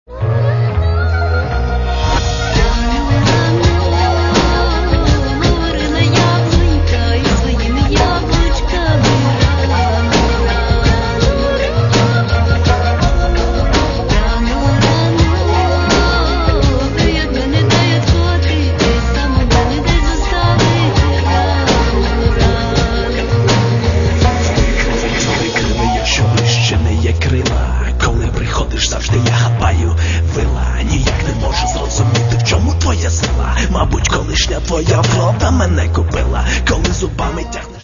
Каталог -> Хіп-хоп